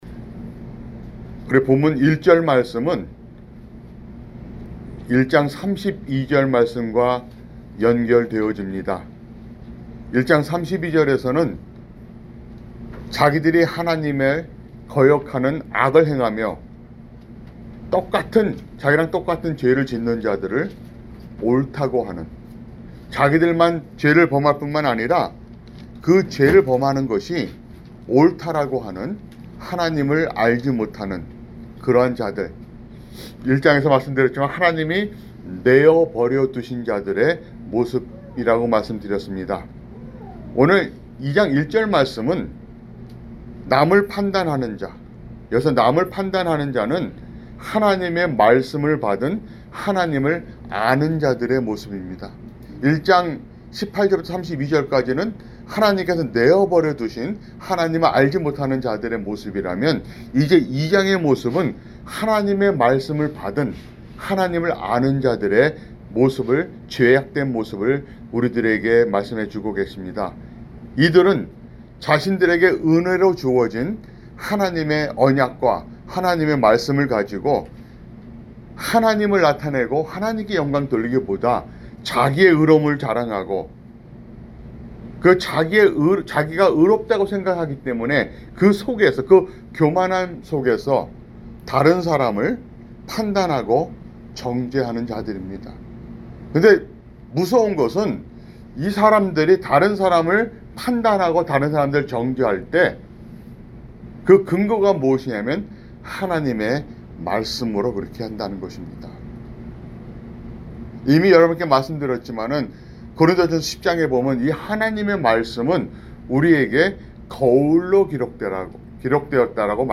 [주일설교] 로마서